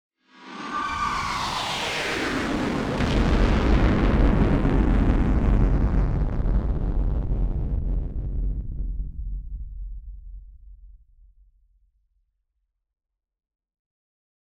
BF_DrumBombDrop-05.wav